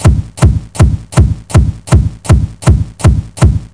1 channel
Walk.mp3